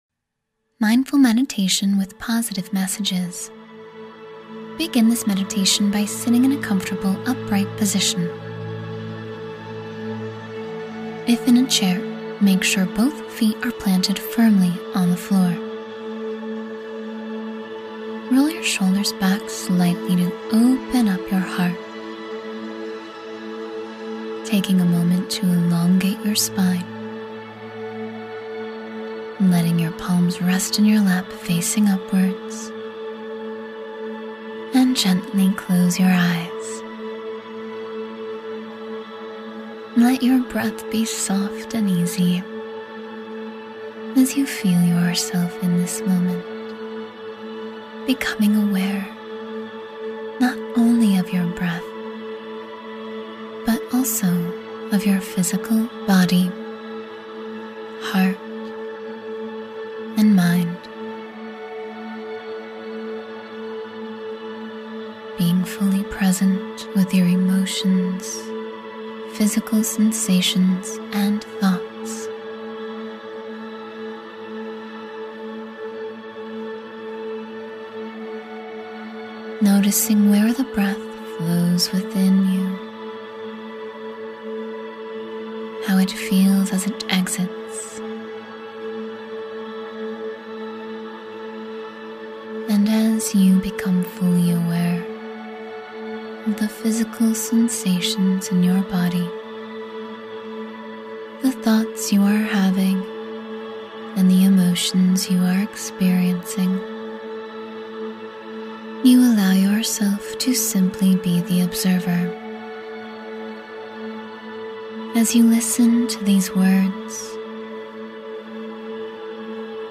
Embrace Unconditional Love — 10-Minute Meditation to Cultivate Peace